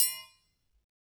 Triangle6-HitM_v2_rr2_Sum.wav